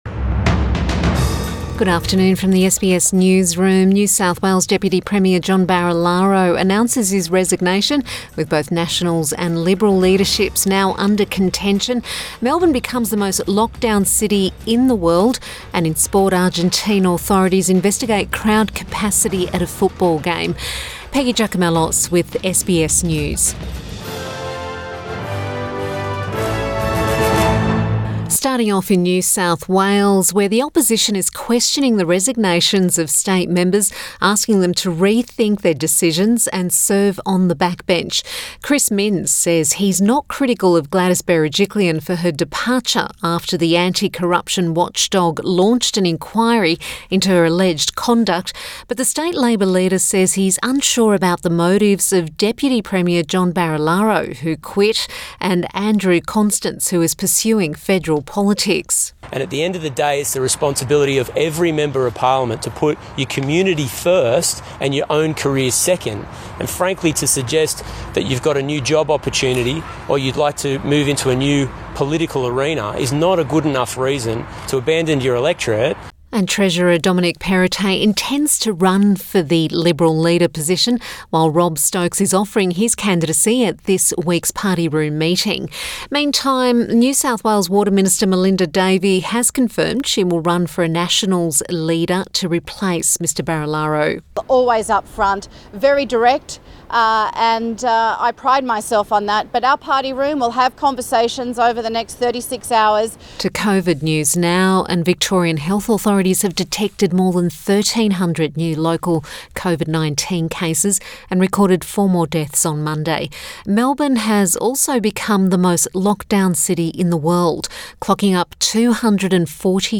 PM bulletin October 4 2021